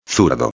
13/09/2011 zurdo canhoto •\ [zur·do] \• •\ Adjetivo \• •\ Masculino \• Significado: Se aplica a la persona que tiene mayor habilidad con la mano y la pierna izquierdas.
zurdo.mp3